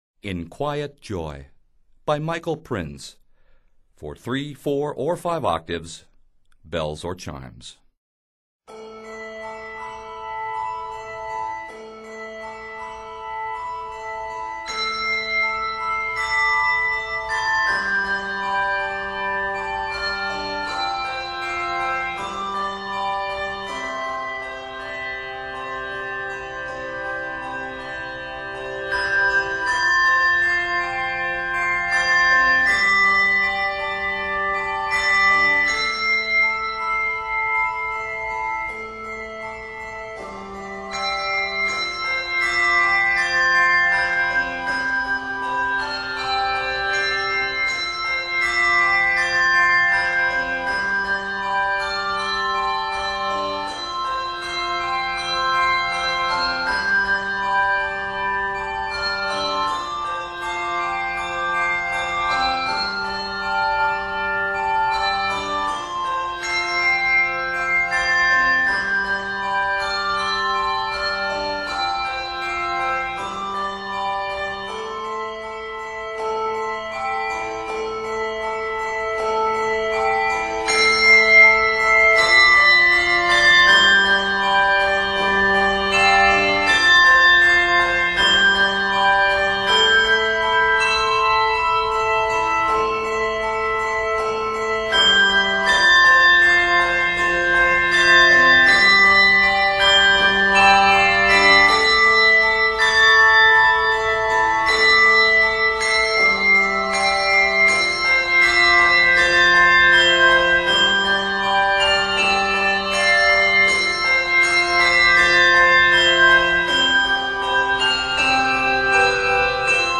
subdued musical meditation
in a minor key (a minor) instead of its usual major tonality